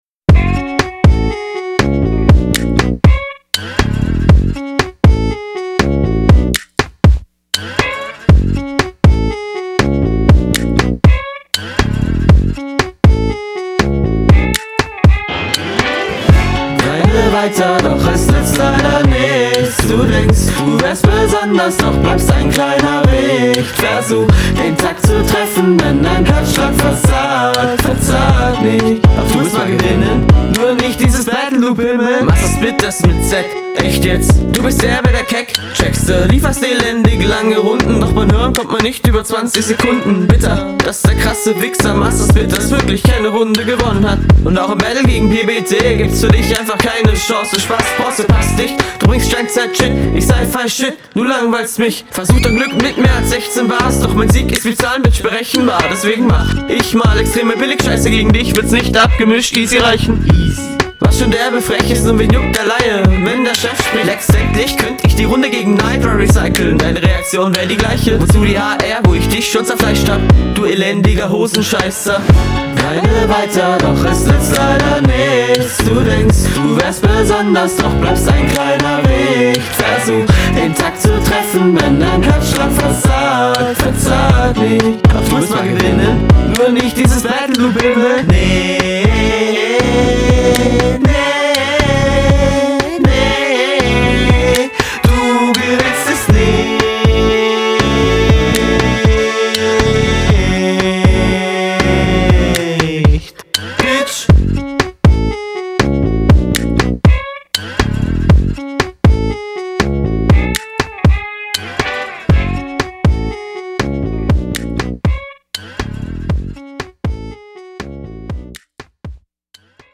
Der Sound um das Mikrofon falsch herum zu verwenden